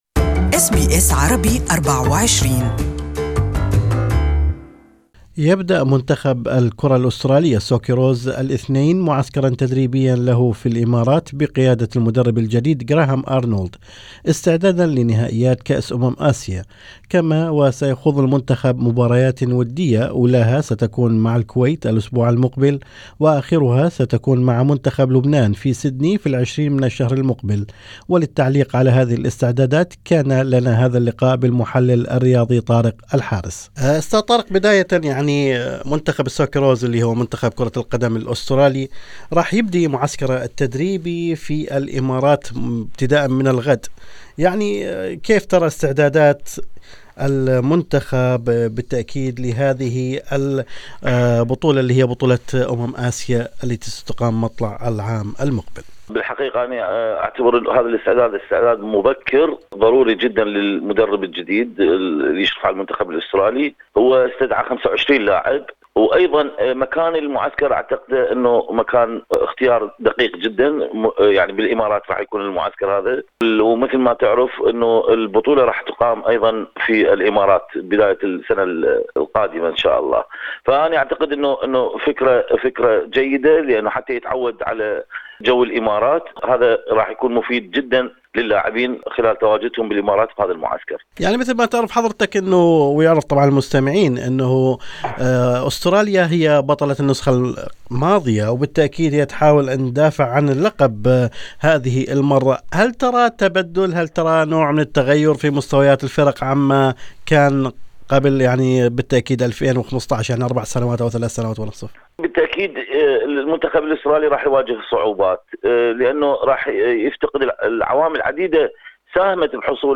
وللتعليق على هذه الاستعدادات كان لنا هذا اللقاء بالمحلل الرياضي